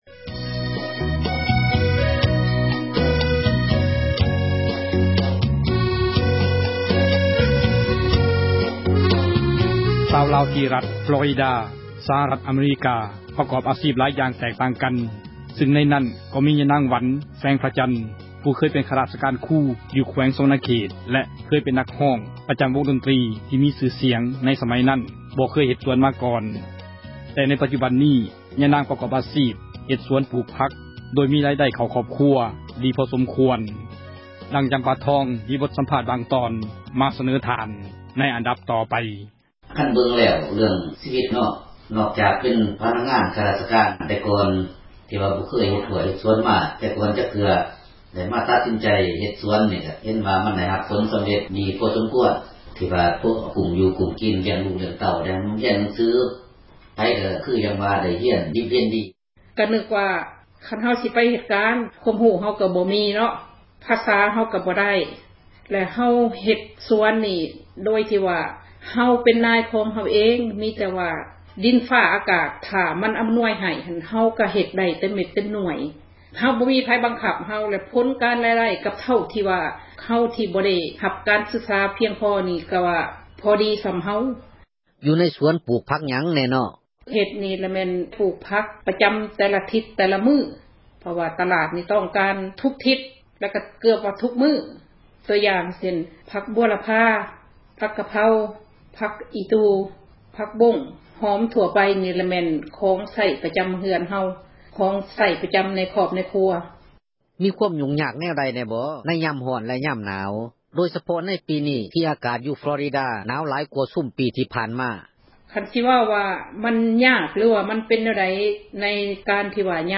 ສັມພາດຊາວສວນ ທີ່ຣັດຟລໍຣີດາ